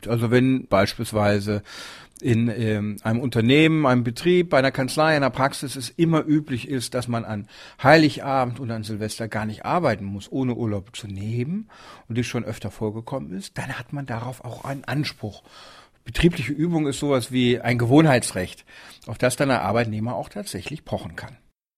O-Töne / Radiobeiträge, Ratgeber, Recht, , ,